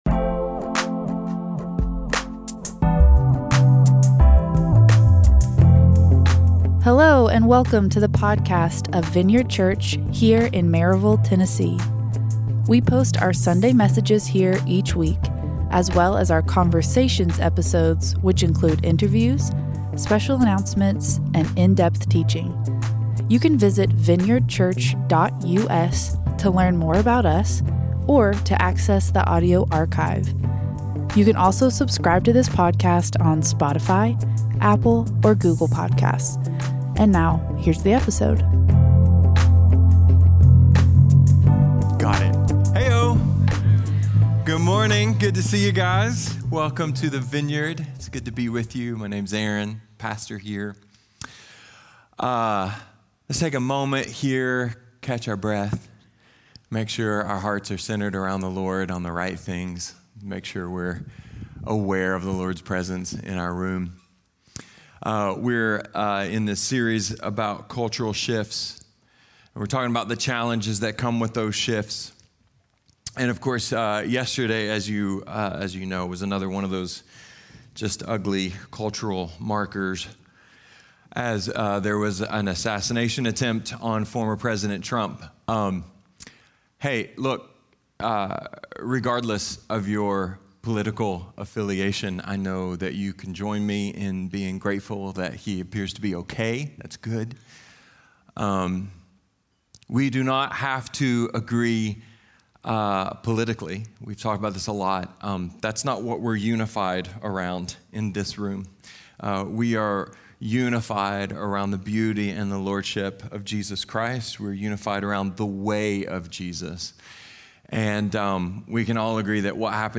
A sermon about how other cultures view the world, how our culture is shifting, and how the timeless gospel applies to all of it!